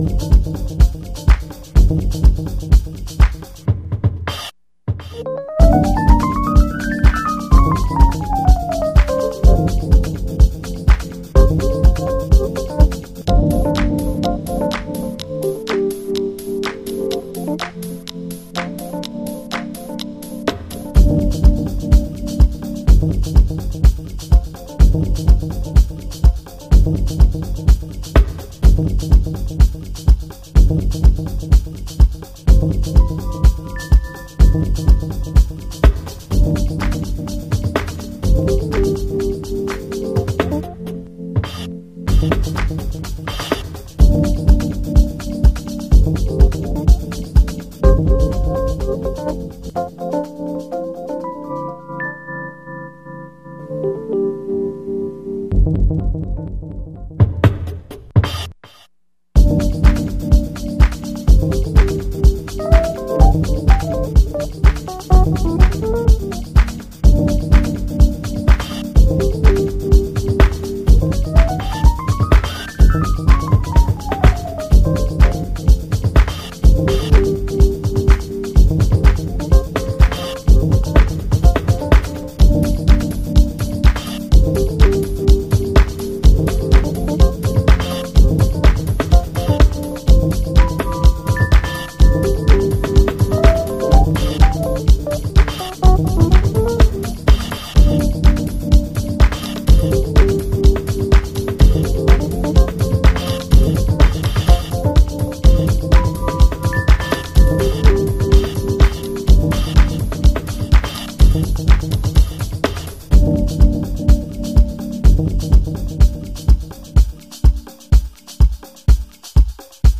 A sweet downtempo track with deep moods
Slamming deep groovin